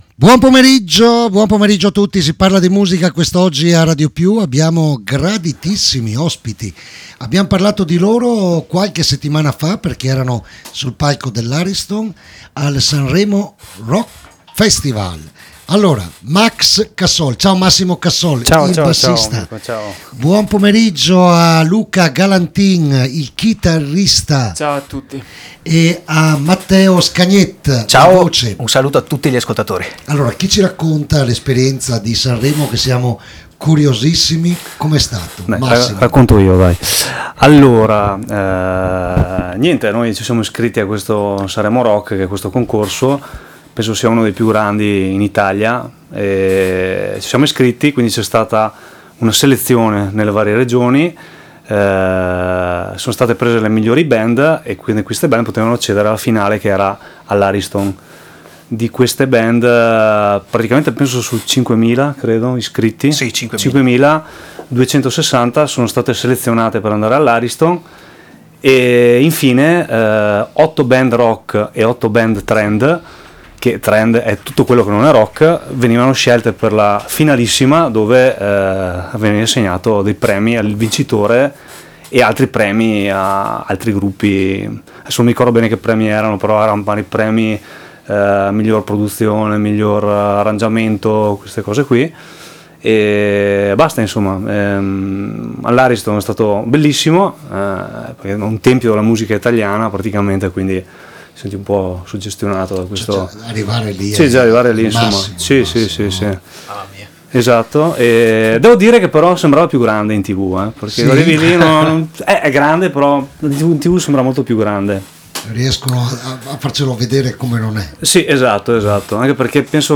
ALICE FOR PRESIDENT A RADIO PIU studio2
IERI ALLA RADIO